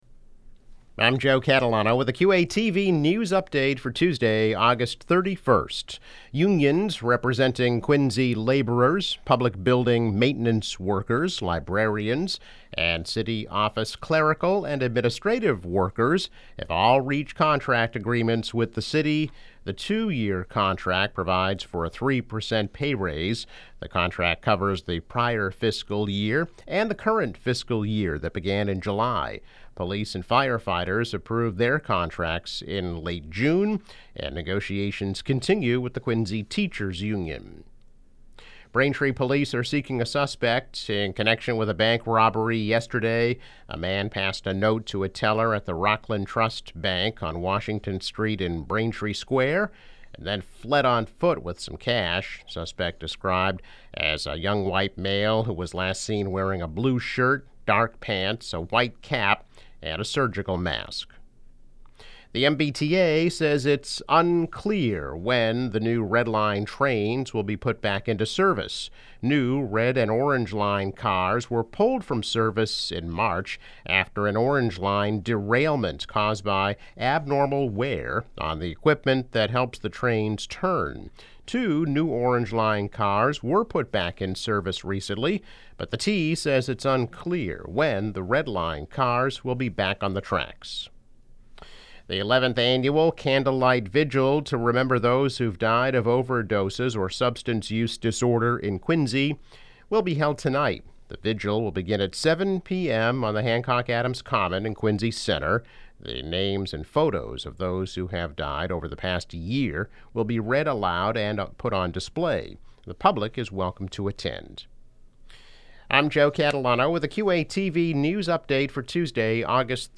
News Update - August 31, 2021